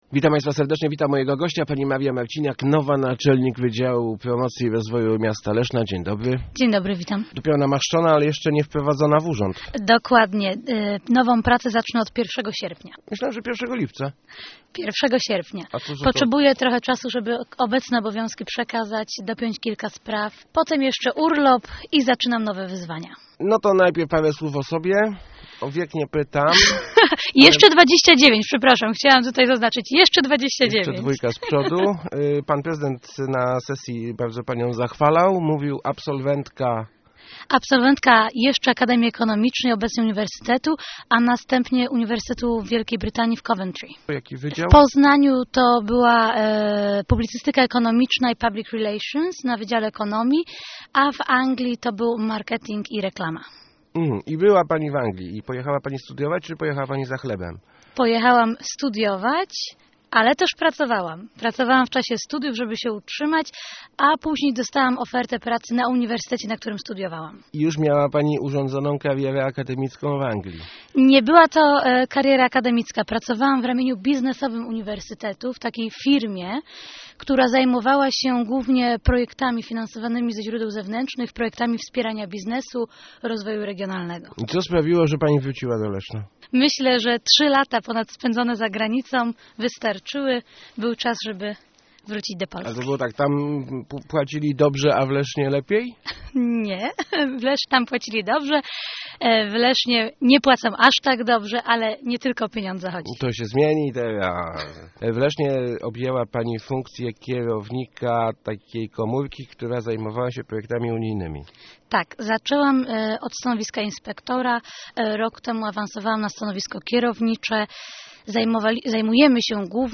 Start arrow Rozmowy Elki arrow Nowa szefowa promocji